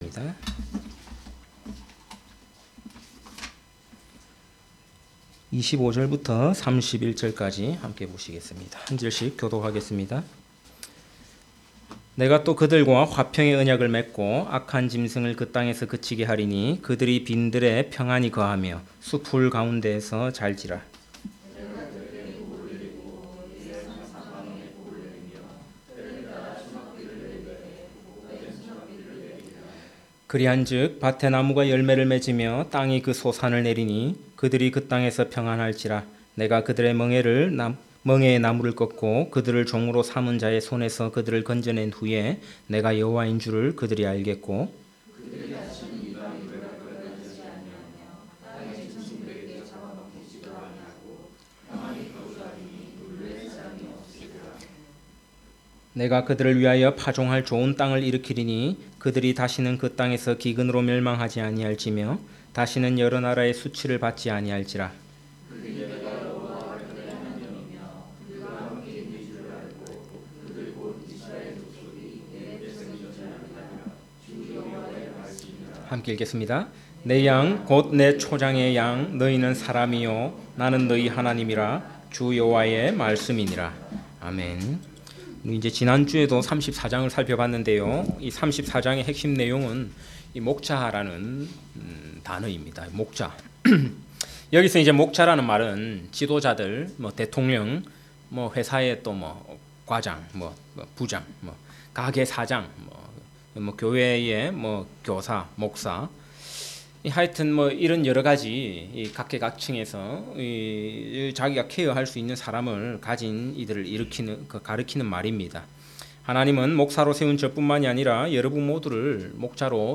에스겔 강해(36) [34:25-31] 설교 듣기